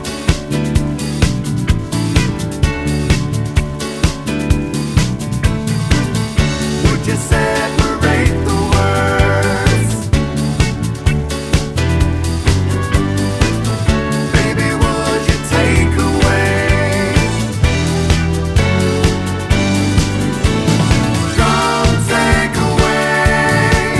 One Semitone Down